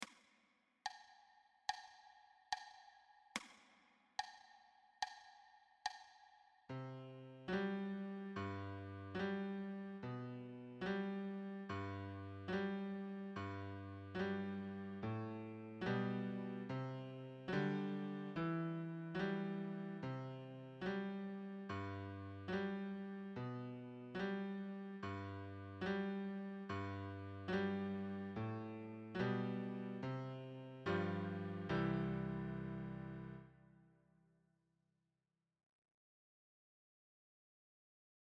Lehrerbegleitung